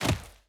Footsteps / Dirt / Dirt Land.wav
Dirt Land.wav